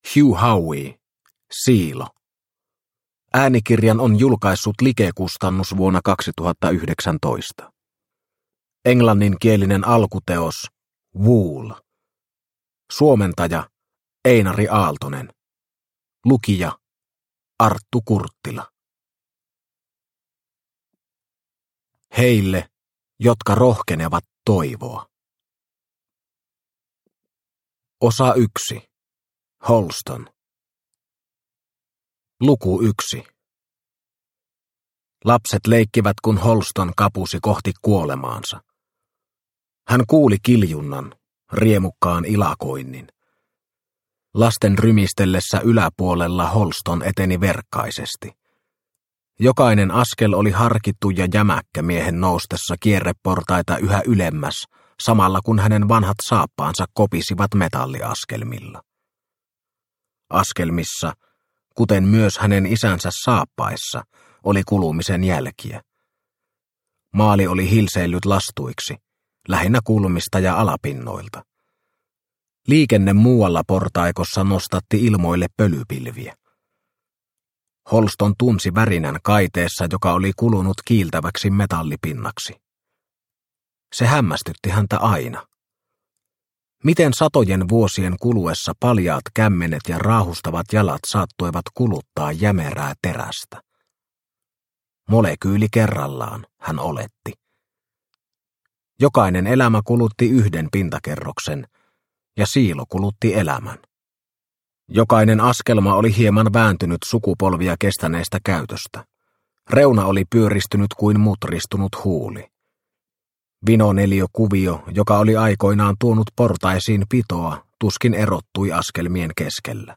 Siilo – Ljudbok – Laddas ner